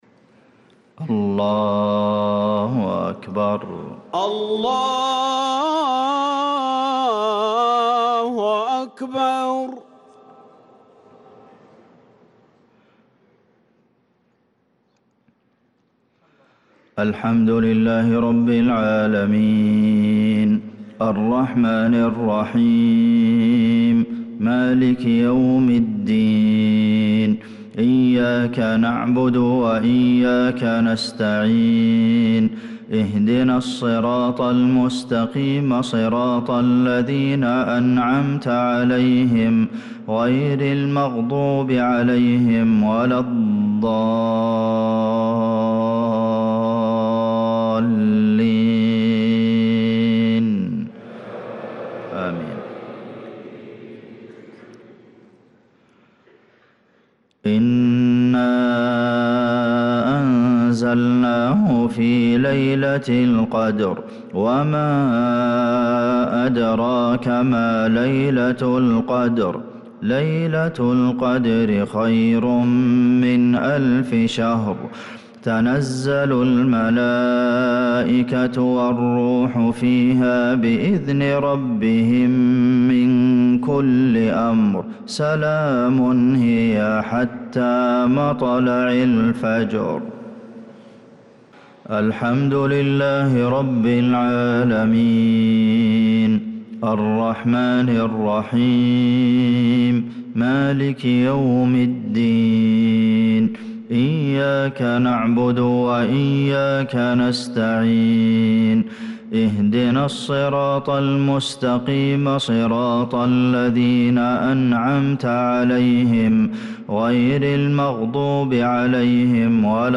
صلاة المغرب للقارئ عبدالمحسن القاسم 21 ربيع الآخر 1446 هـ
تِلَاوَات الْحَرَمَيْن .